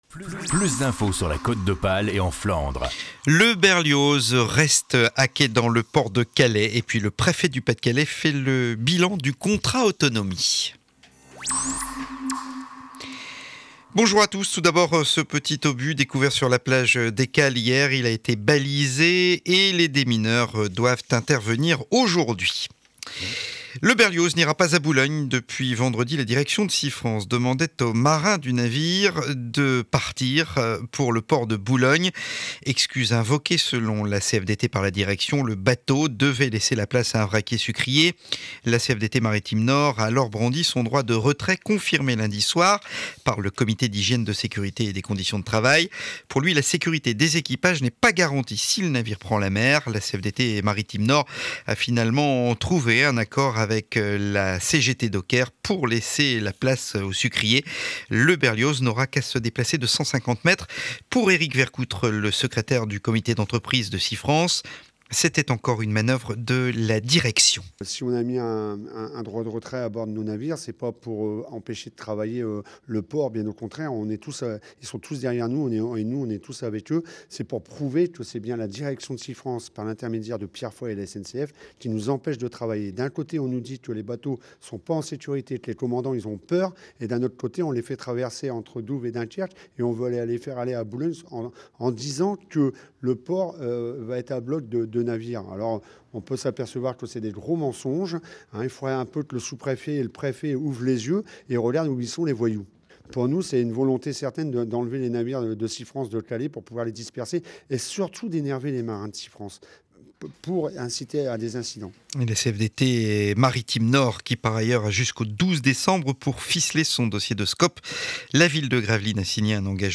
Journal de 12 du mercredi 7 décembre édition de calais.